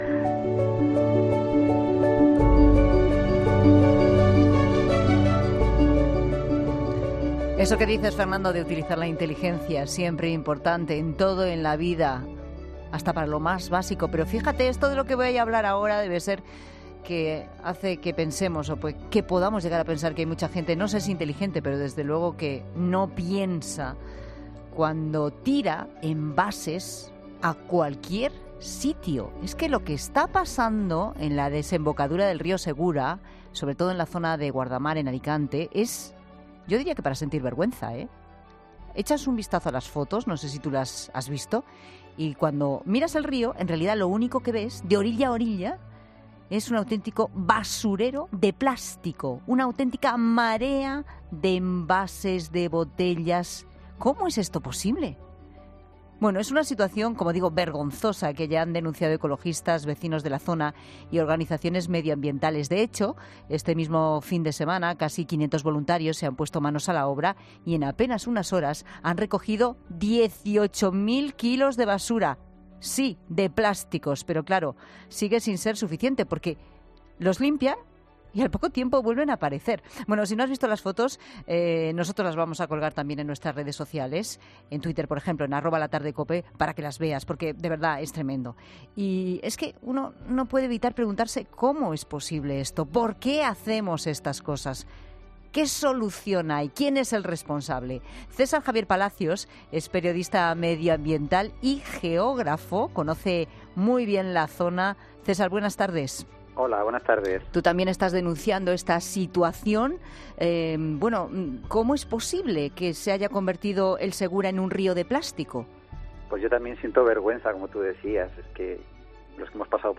El periodista ambiental y geógrafo conocedor de la zona